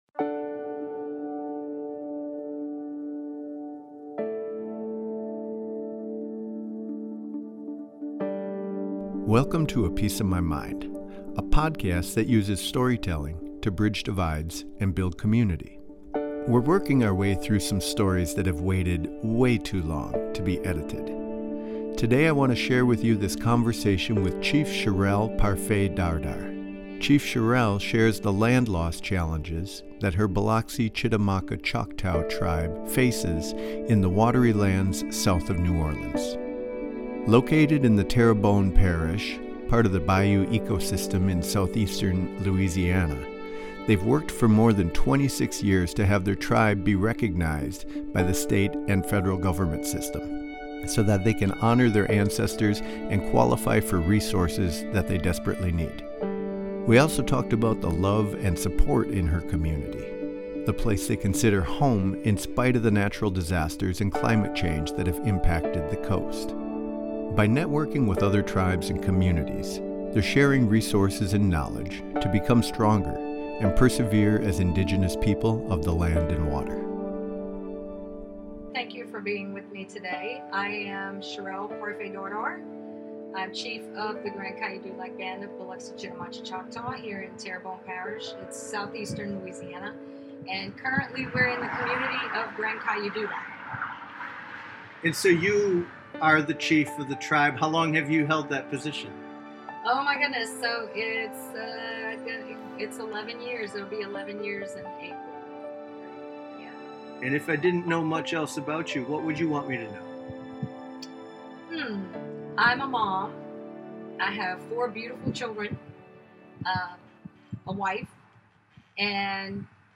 We did this interview on the front porch, on a windy day, along a busy road, so there is some background noise, but the conversation is rich.